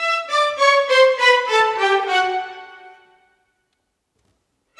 Rock-Pop 20 Violins 02.wav